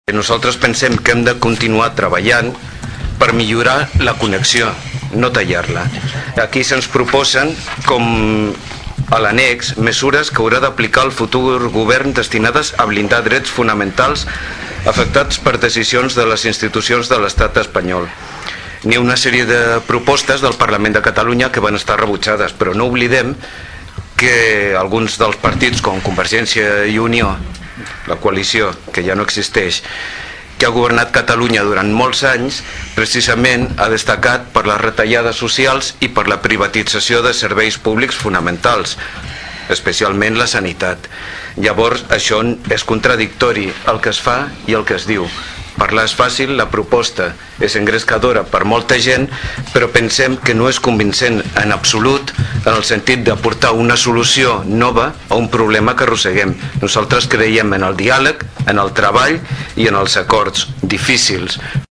El regidor del PSC, Rafa Delgado, justificava el vot en contra del seu partit perquè considera que la moció no proposa un model que funcioni millor que el d’ara.